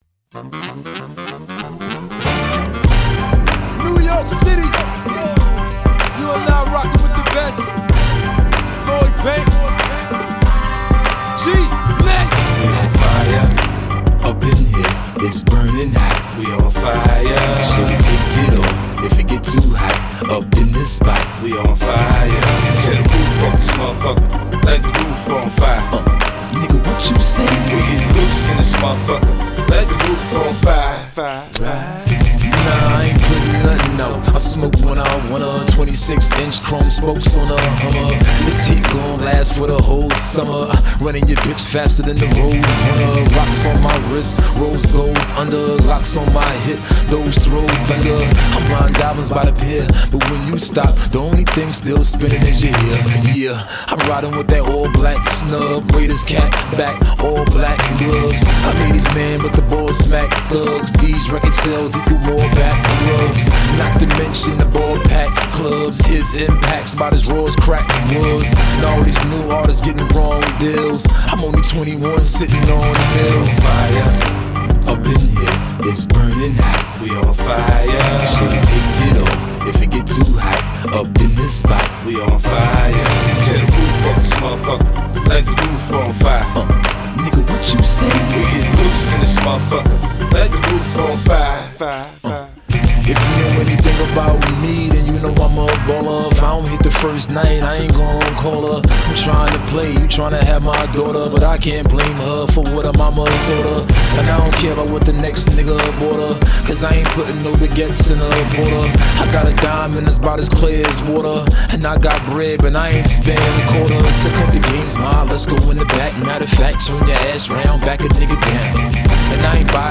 HipHop 2000er